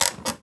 chair_frame_metal_creak_squeak_11.wav